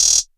BWB VAULT HAT ROLLS (Drill).wav